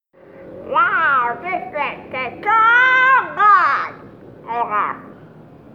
cartoon donald duck funny silly voice sound effect free sound royalty free Funny